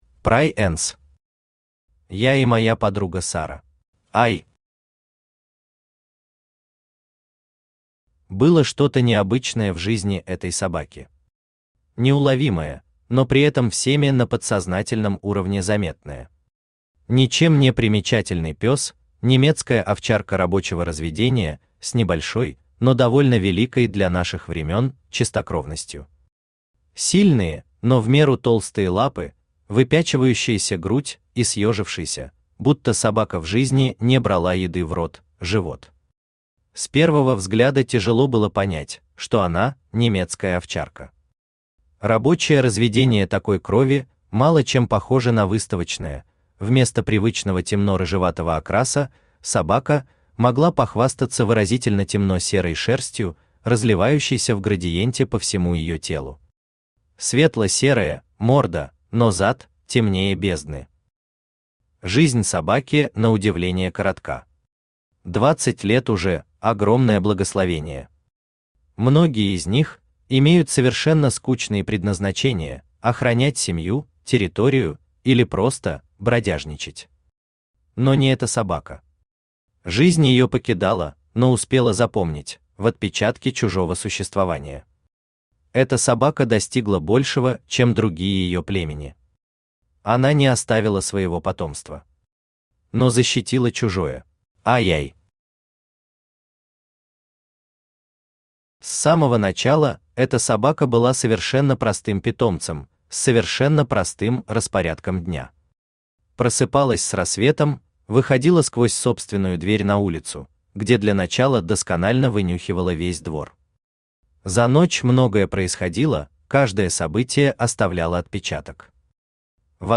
Aудиокнига Я и Моя подруга Сара Автор Prai'ns Читает аудиокнигу Авточтец ЛитРес.